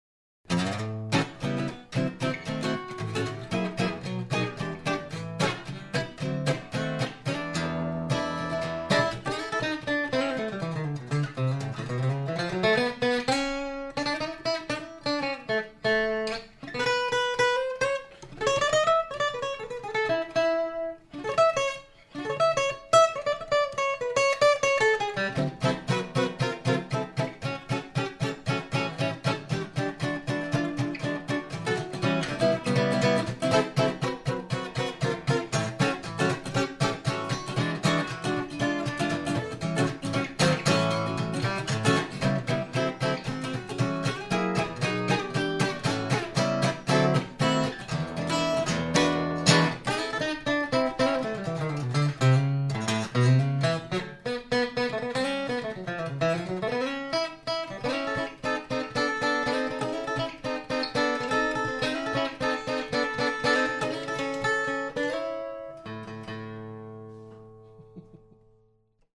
This is a 40th Anniversary Beograd Archtop model featuring Bozo’s signature ornate Abalone inlays and elaborate custom purfling. The maple back and sides and European Spruce top make for a rich and powerful voice.